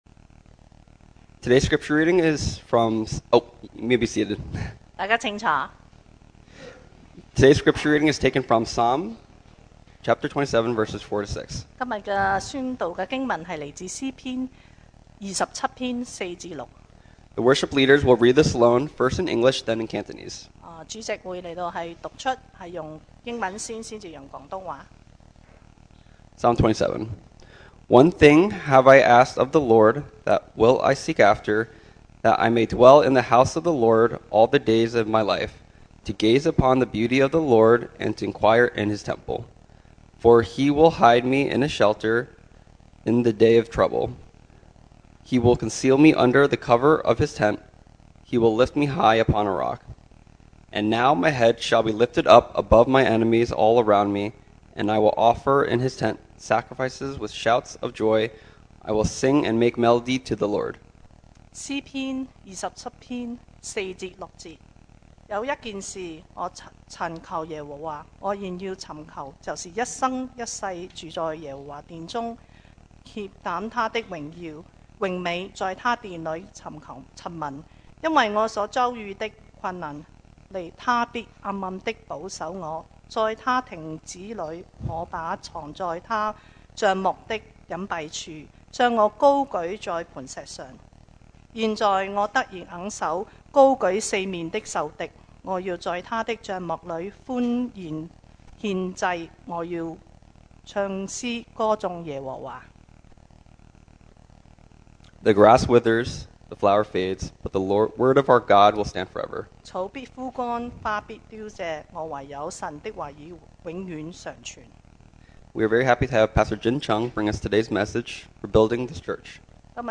Passage: Psalm 27:4-6 Service Type: Sunday Morning